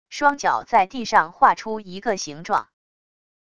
双脚在地上划出一个形状wav音频